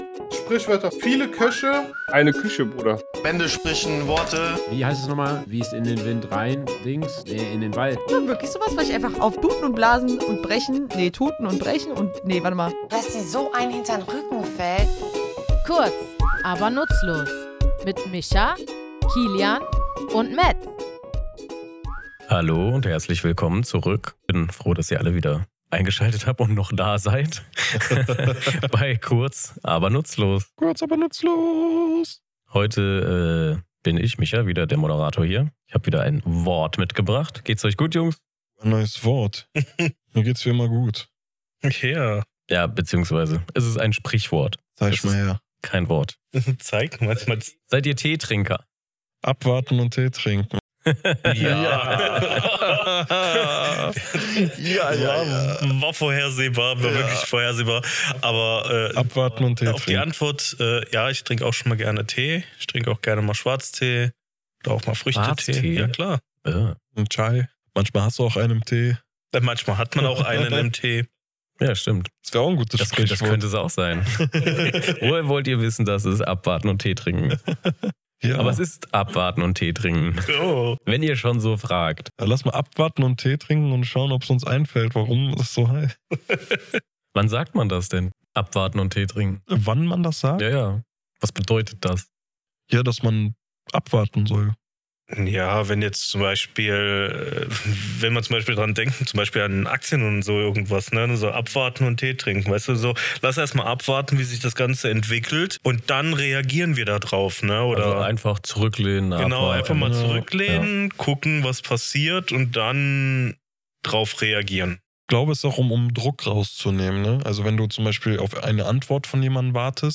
Comedy
Setzt euch zu uns ins Tattoostudio, lehnt euch zurück und